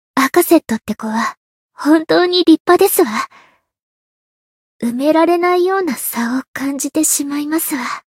灵魂潮汐-安德莉亚-闲聊-同伴印象.ogg